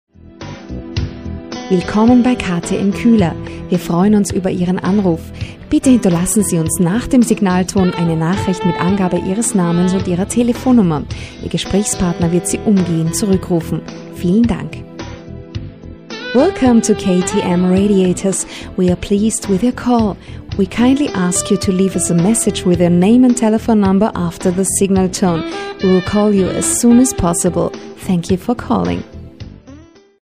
Stimmbeschreibung: Warm, einfühlsam, erotisch/sexy, verführerisch, werblich, jung oder reif, elegant, sympatisch, souverän, geheimnissvoll, sachlich/kompetent, erzählerisch, selbstbewusst und wandlungsfähig.
Sprecherin und Sängerin. Stimme: Warm, einfühlsam, erotisch / sexy, verführerisch, jung oder reif, elegant, sympatisch, souverän, geheimnissvoll,
Sprechprobe: Sonstiges (Muttersprache):